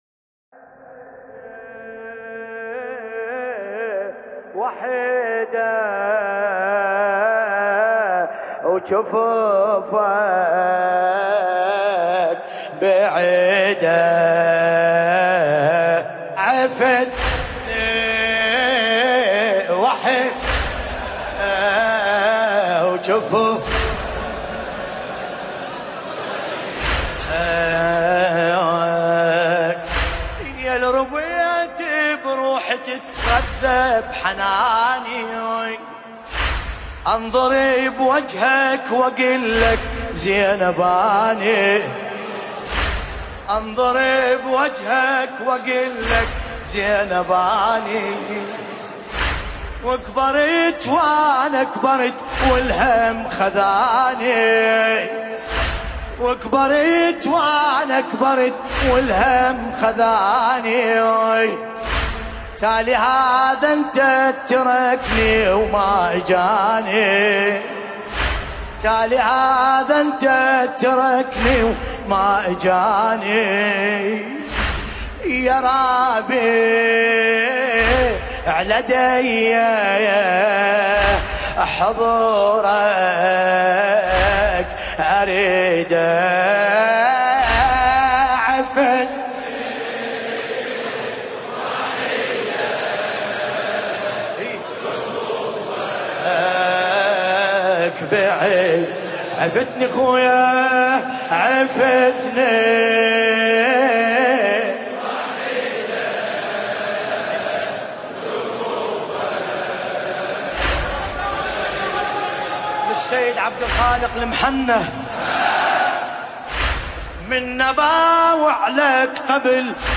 ليالي محرم ۱۴۴۳ هـ لطمية